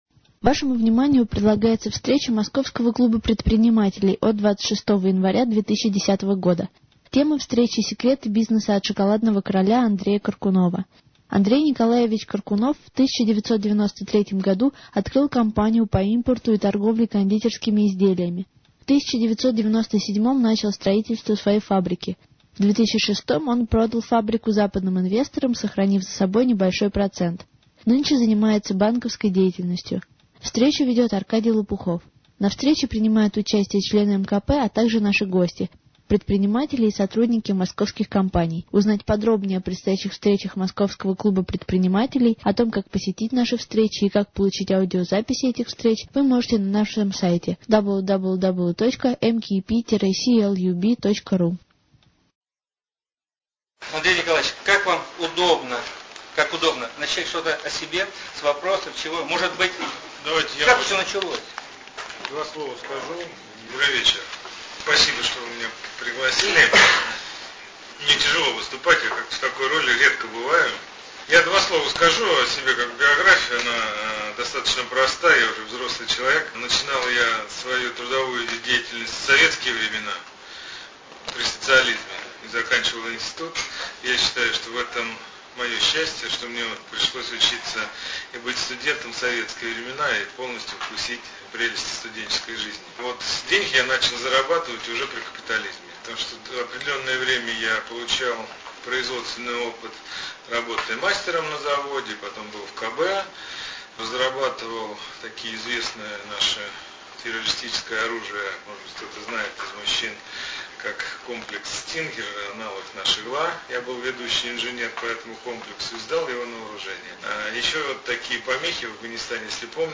Мероприятие ОТКРЫТАЯ встреча МКП. У нас в гостях Андрей Коркунов